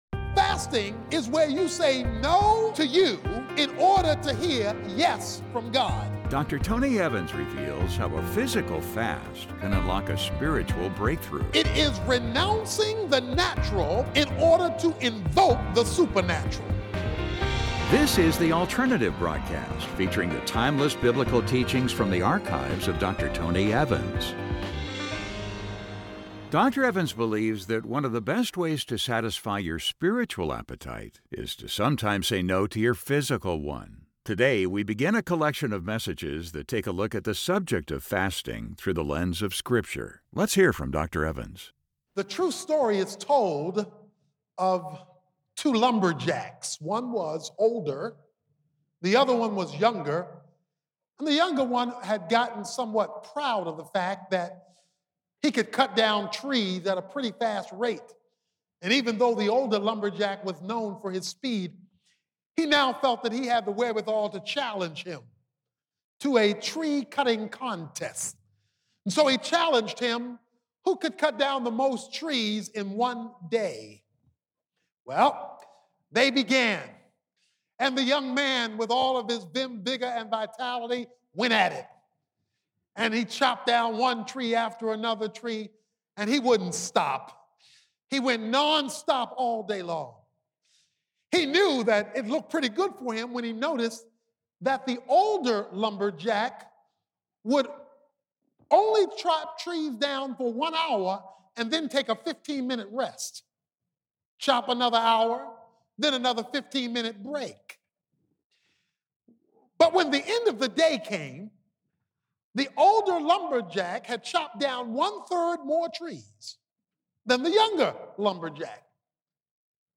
Dr. Tony Evans believes that one of the best ways to satisfy your Â spiritual Â appetite is to sometimes say no to your Â physical Â one. In this message, he begins a fascinating look at the subject of fasting through the lens of scripture.